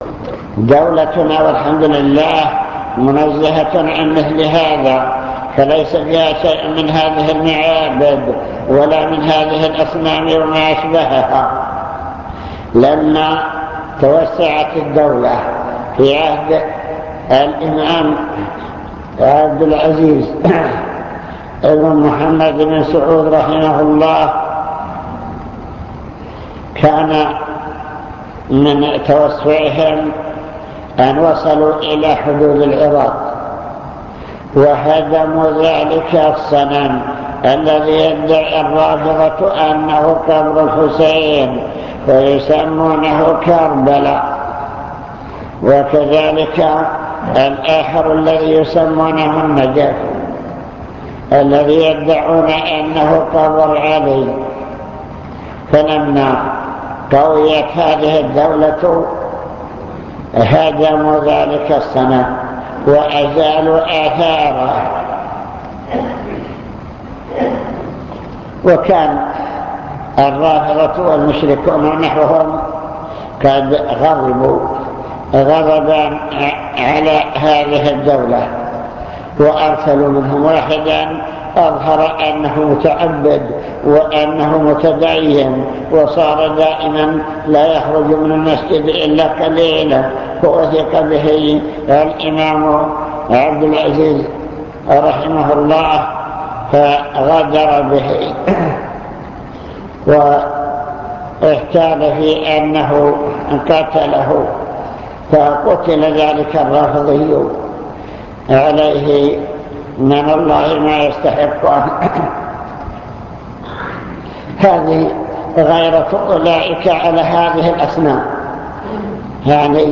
المكتبة الصوتية  تسجيلات - محاضرات ودروس  محاضرة في أحكام التصوير